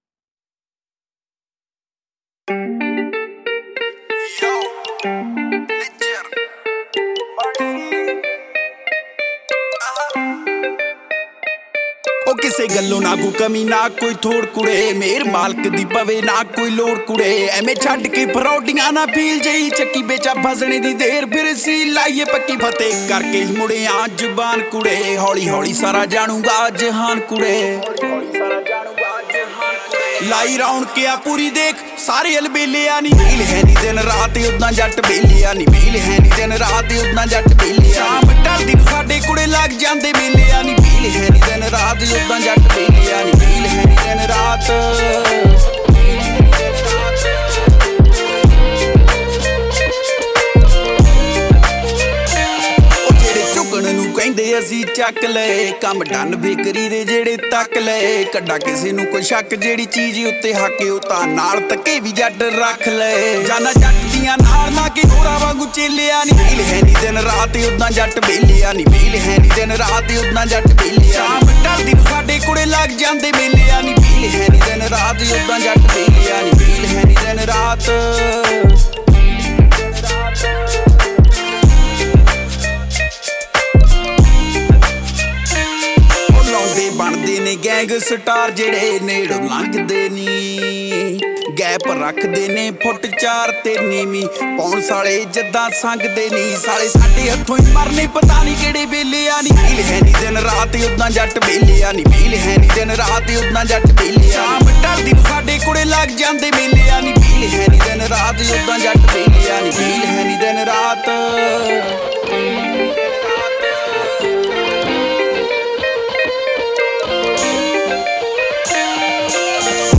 Punjabi Music Album